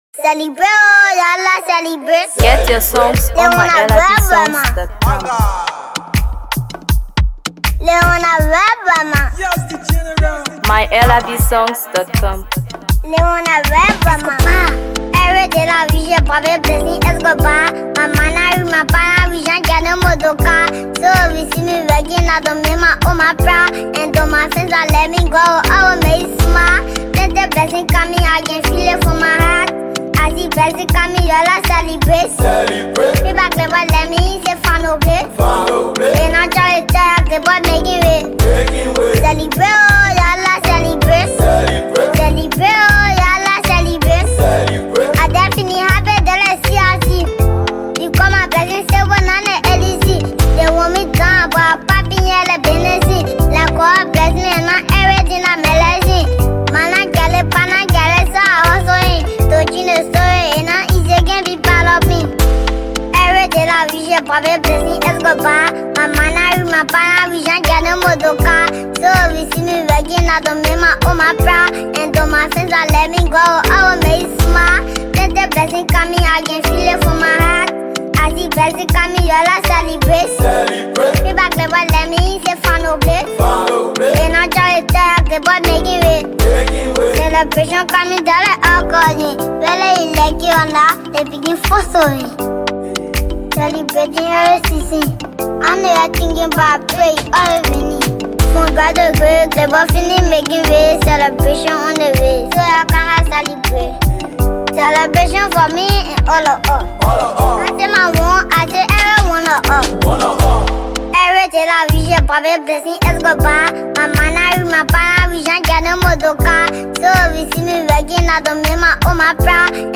Afro PopMusic
melodic vocals